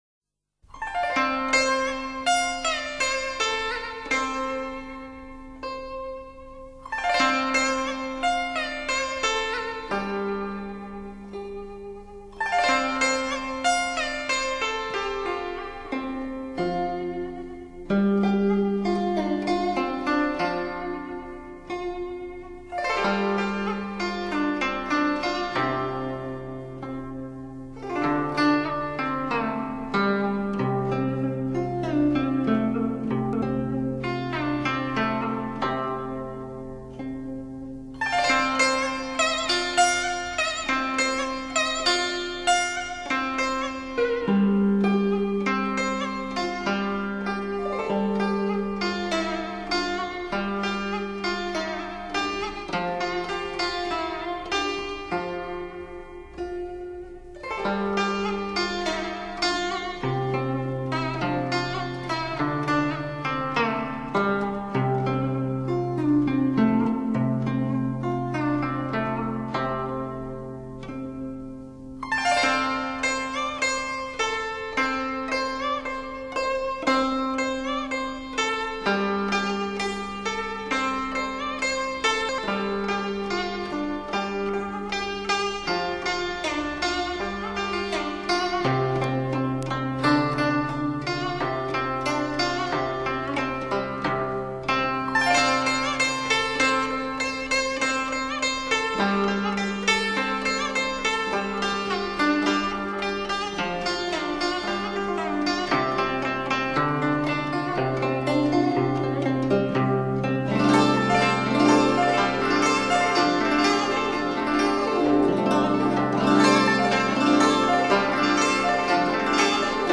用的是当时全新数位(DDD)录音技术
但此碟音色决不差，声音晶莹透彻，沁人心脾